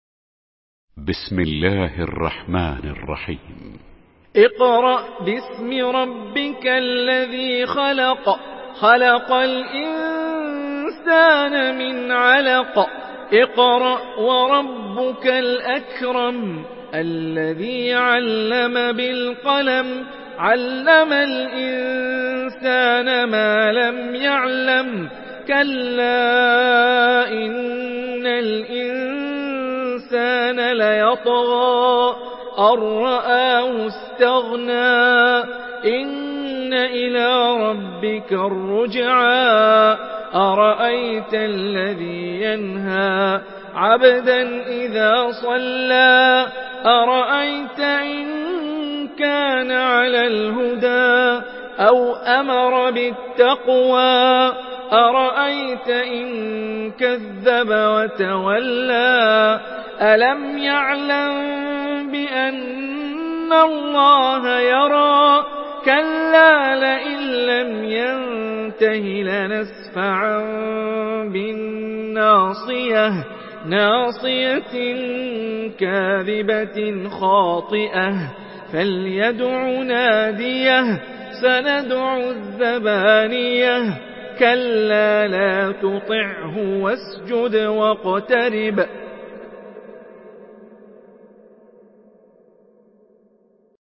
Surah আল-‘আলাক্ব MP3 by Hani Rifai in Hafs An Asim narration.
Murattal Hafs An Asim